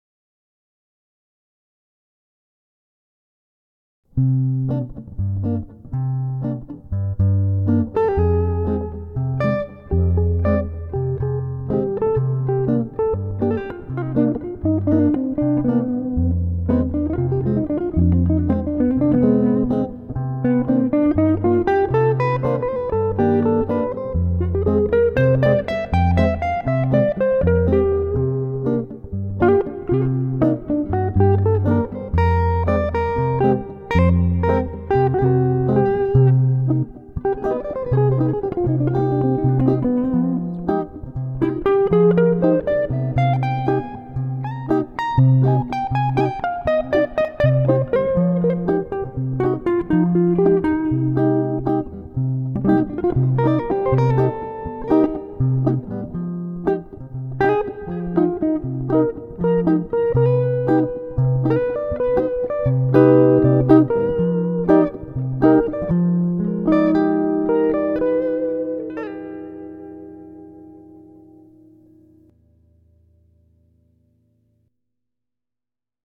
bon , j'ai enregistré un p'tit délire sur || : Cm7(9) | % | Fm7 | % : ||
il y a à peu près tous les ingrédients dont j'ai parlé plus haut et en particulier des mineurs mélodiques sur accords mineurs 7.
à plusieurs reprises , en particulier sur la fin , j'insiste plutôt fortement sur la 7M.
ici , pour moi ça reste un exercice de style : une véritable improvisation doit être libre...là , je me suis contraint à utiliser ces trucs.
bien sur , on pourra toujours dire qu'on aime pas ou que telle note frotte avec telle autre...enfin ...c'est si dissonant?